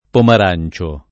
vai all'elenco alfabetico delle voci ingrandisci il carattere 100% rimpicciolisci il carattere stampa invia tramite posta elettronica codividi su Facebook Pomarancio [ pomar # n © o ] soprann. — di vari pittori nativi di Pomarance (sec. XVI-XVII)